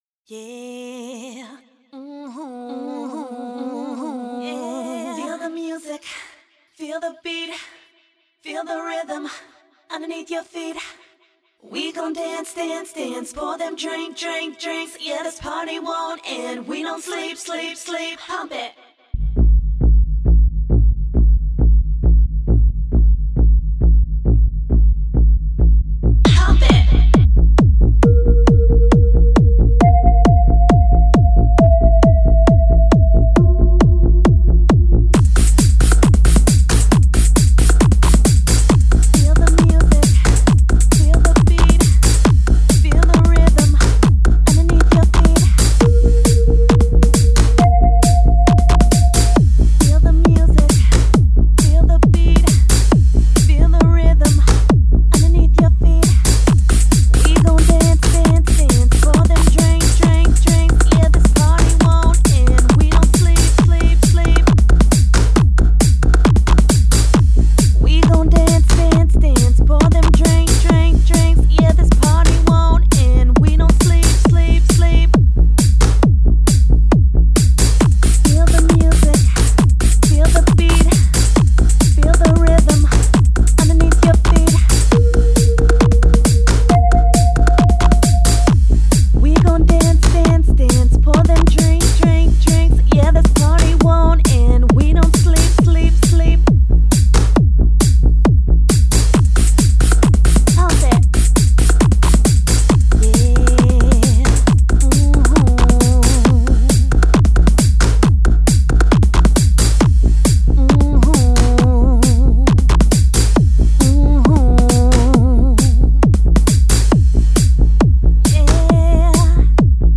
Electronic
Electro House
Mood: Party Music